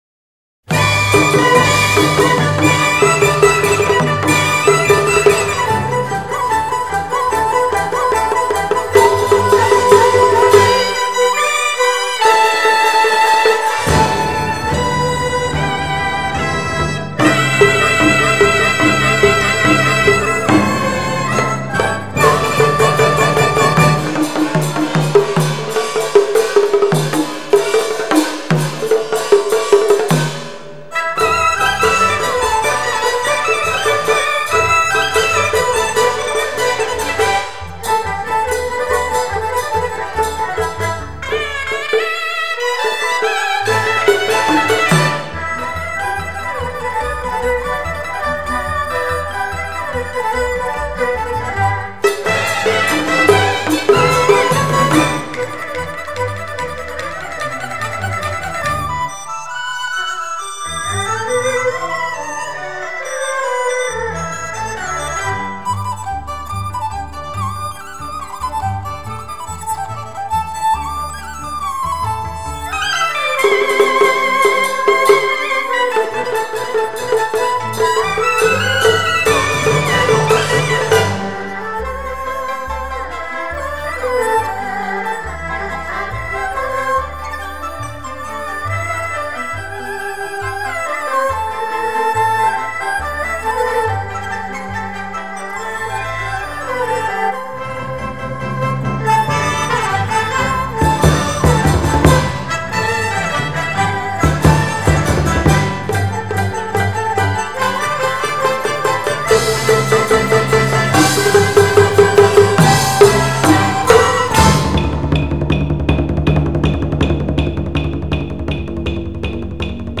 开创3D录音新纪元，不仅有上佳的清晰度，而且乐器的
鼓樂大合奏